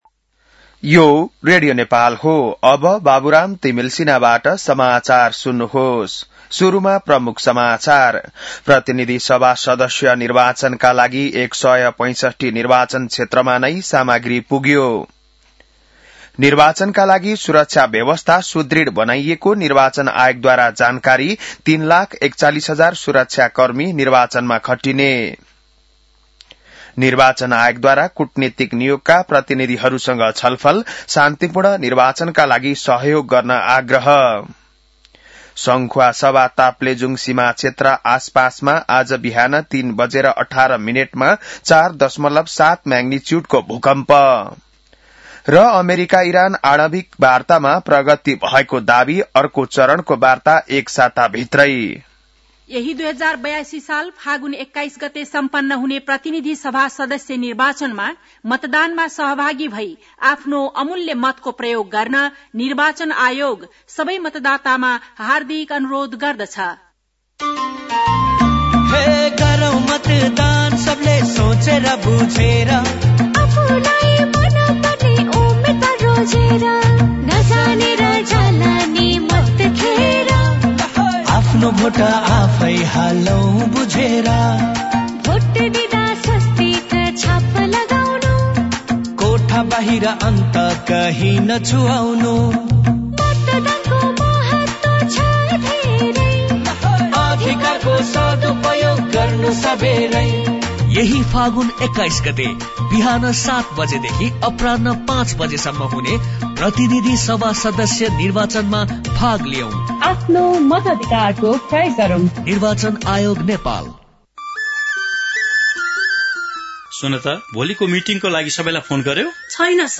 बिहान ७ बजेको नेपाली समाचार : १५ फागुन , २०८२